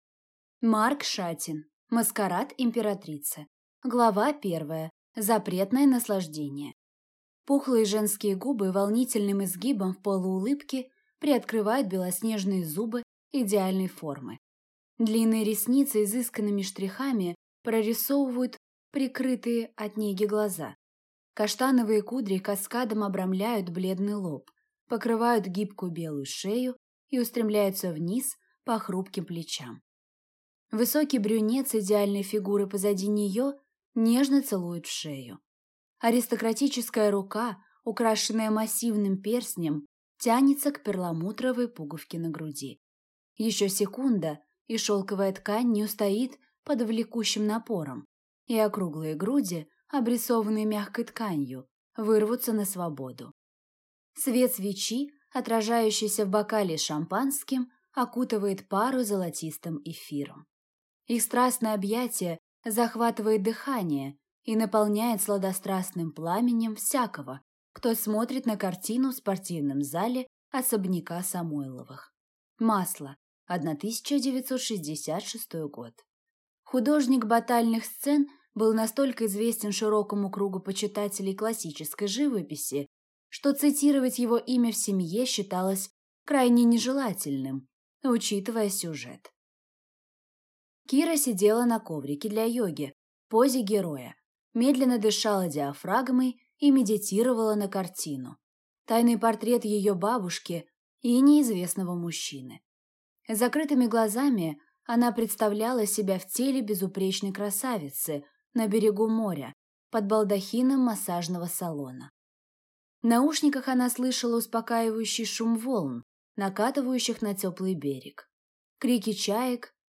Аудиокнига Маскарад императрицы | Библиотека аудиокниг